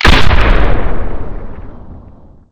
explosionLargeNear.ogg